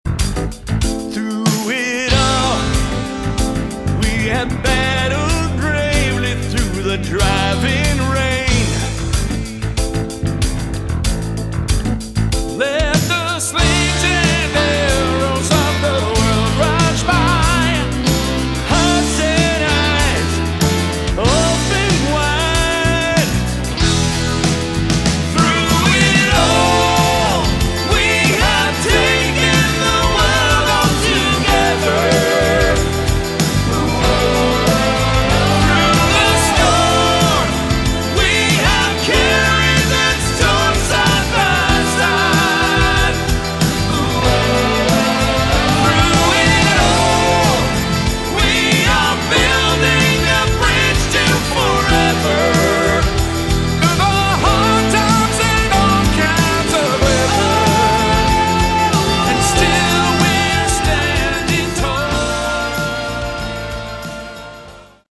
Category: AOR